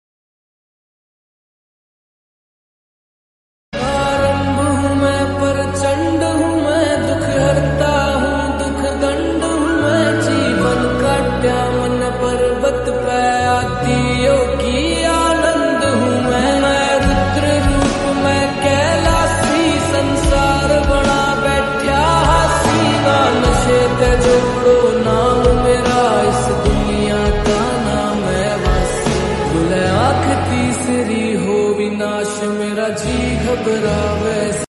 Categories Devotional Ringtones